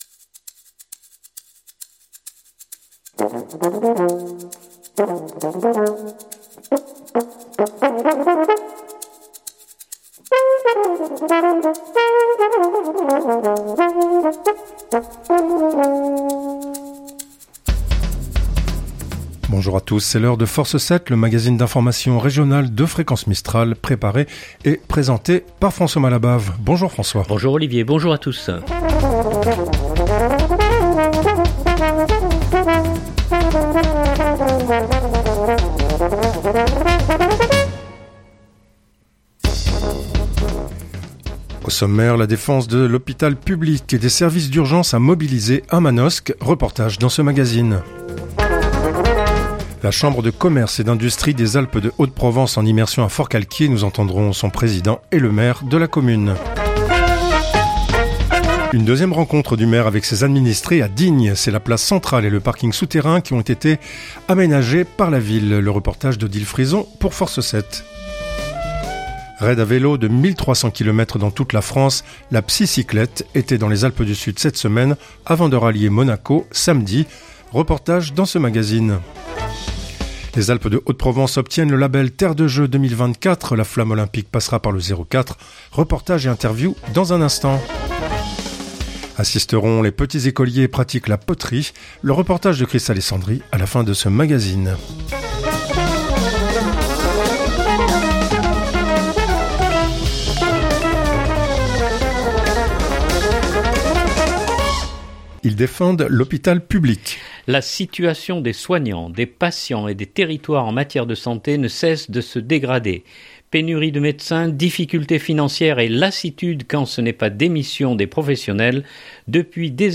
Au sommaire : - La défense de l’hôpital public et des services d’urgence a mobilisé à Manosque. Reportage dans ce magazine.
Reportage et interviews dans un instant.